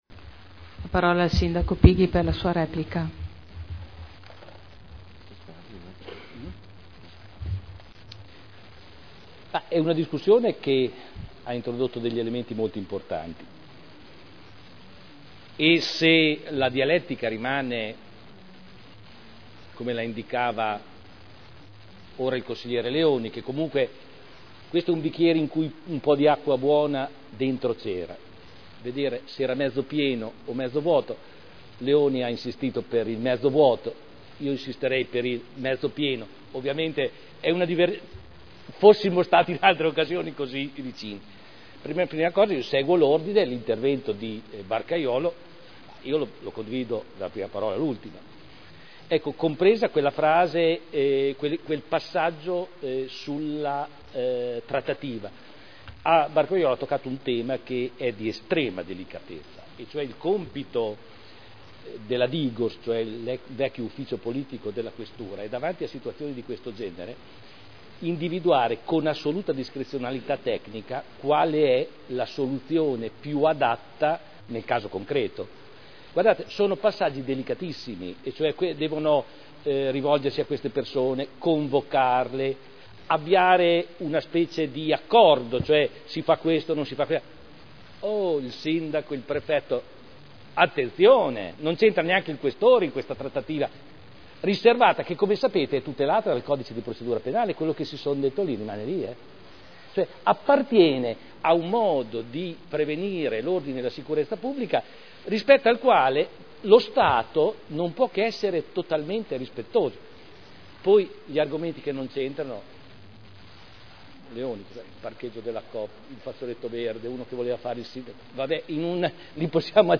Audio Consiglio Comunale
Seduta del 12/07/2010